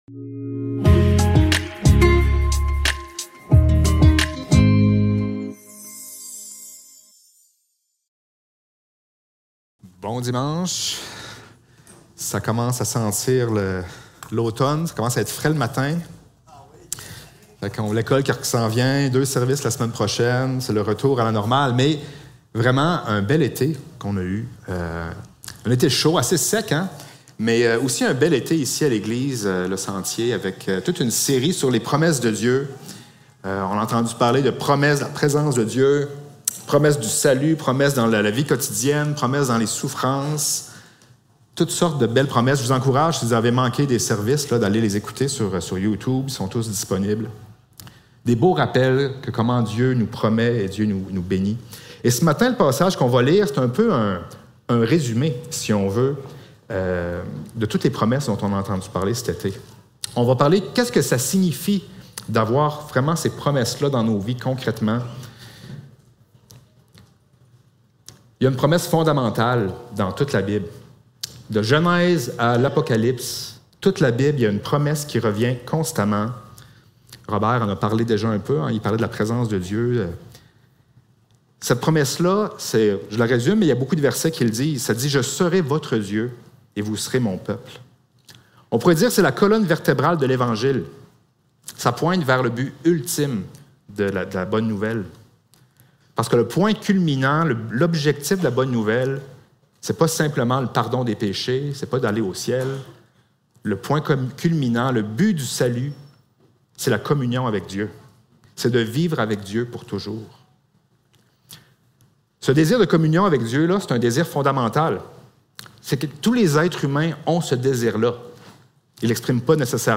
2 Corinthiens 6.14- 7.1 Service Type: Célébration dimanche matin Description